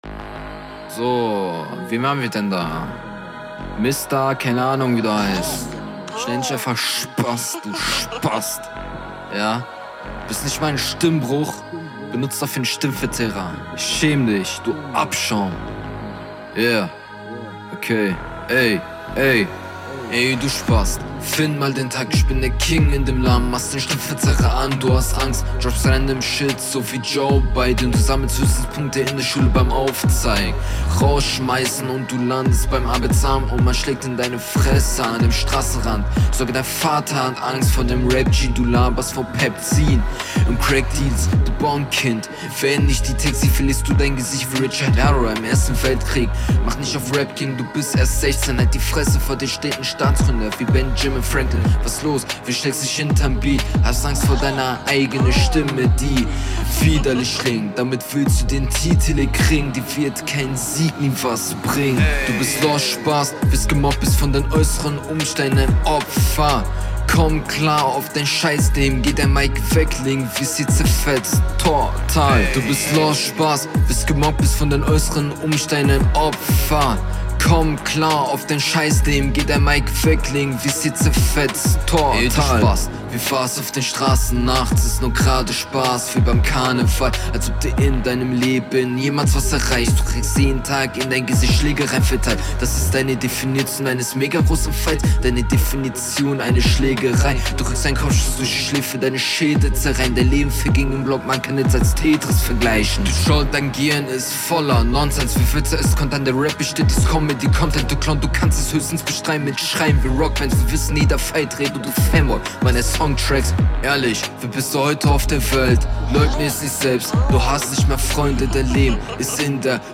undeutlich und teilweise bisschen offbeat